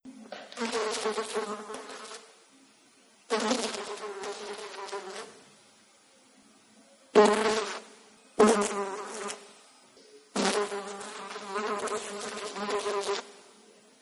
Mosca
Sonido de mosca revoloteando (zumbido)
zumbido
insecto
Sonidos: Animales